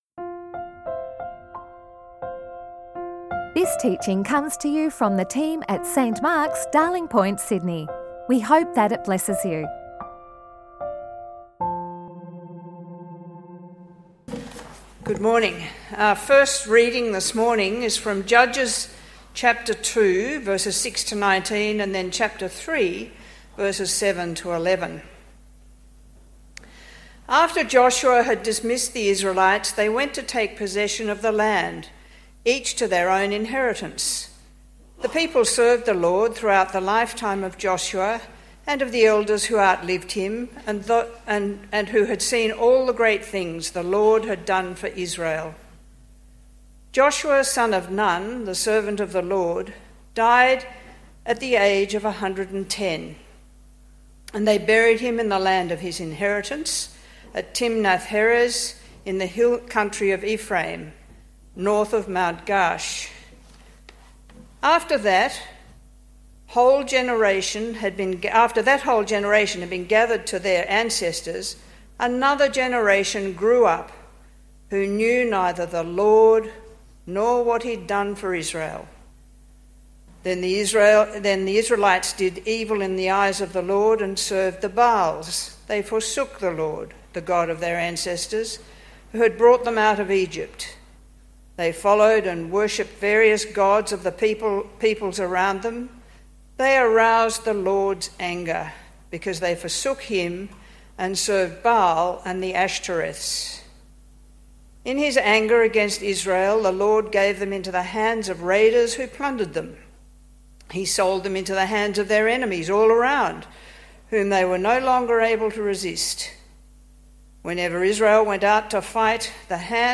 Weekly sermons recorded at St Mark's Darling Point in Sydney, Australia.